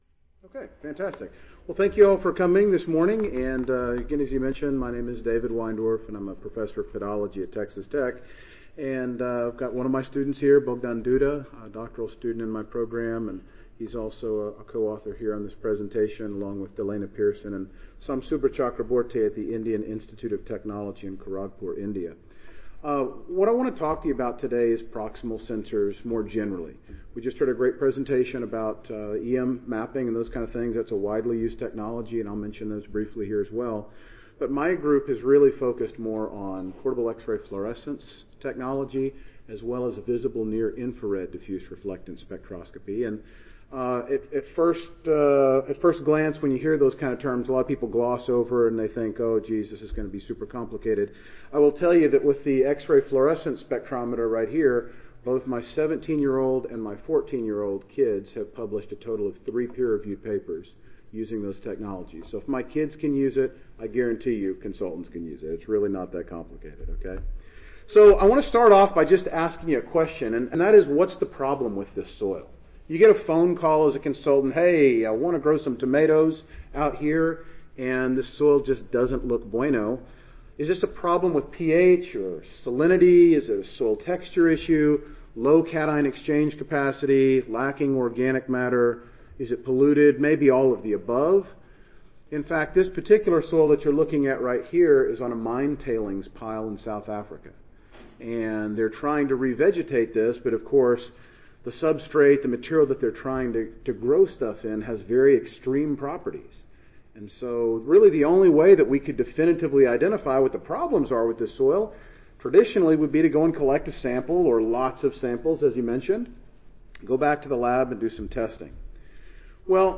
Session: Symposium--Tools and Technologies for Consulting Soil Scientists (ASA, CSSA and SSSA International Annual Meetings)
Texas Tech University Audio File Recorded Presentation